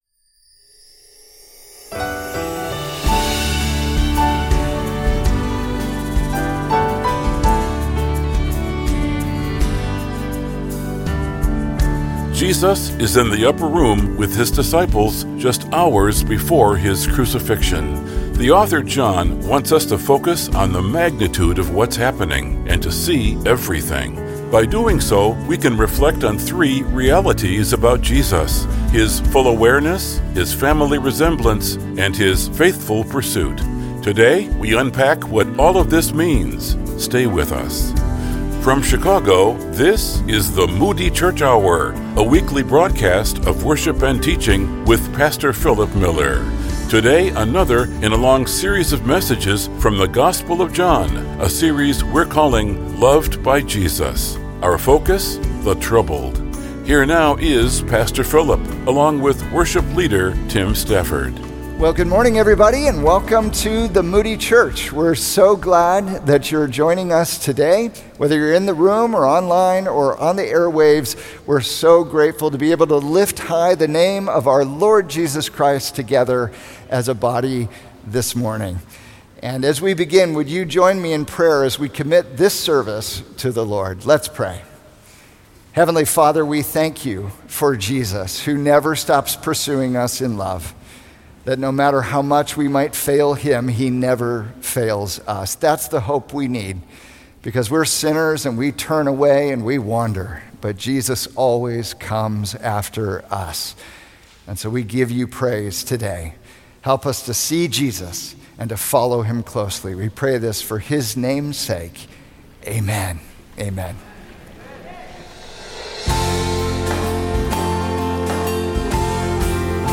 In this message from John 13